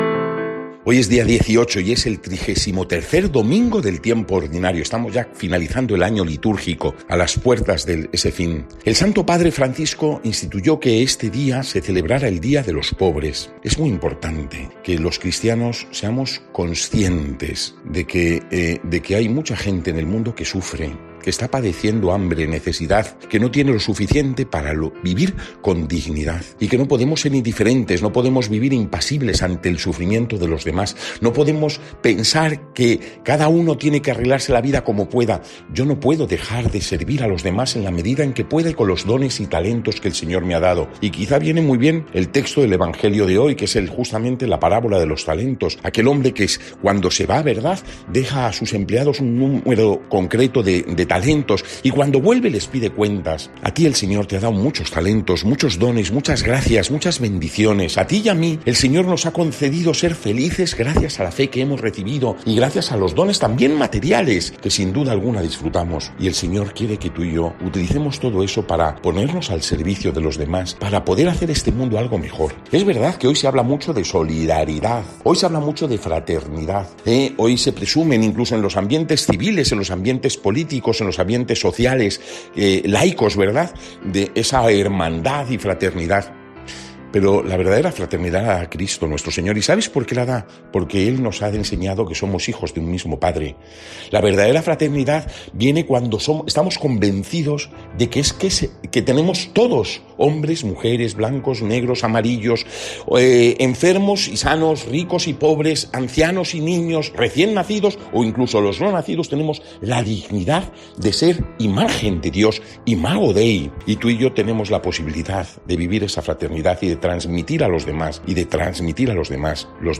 Evangelio según san Mateo (25, 14-30) y comentario